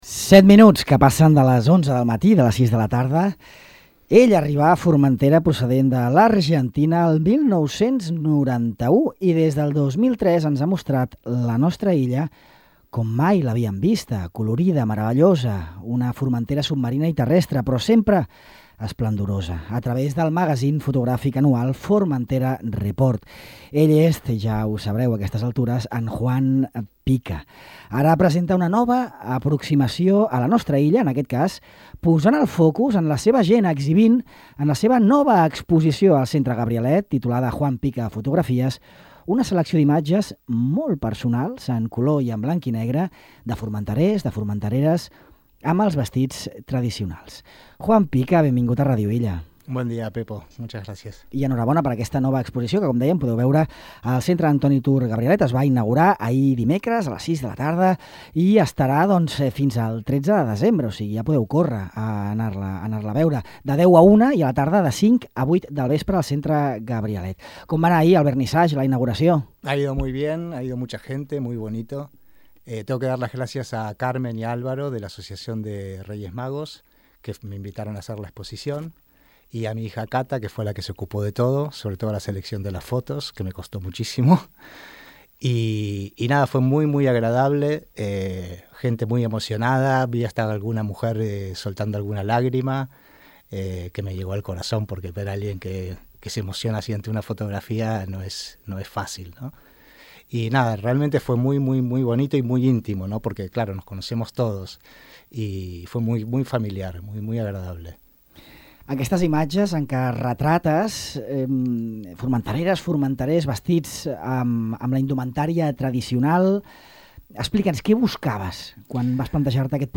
En aquesta entrevista